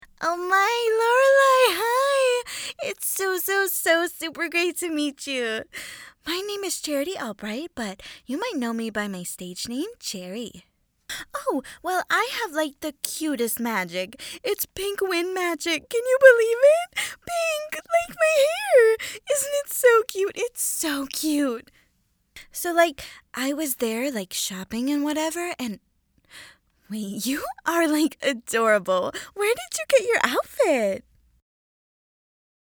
Bubbly young female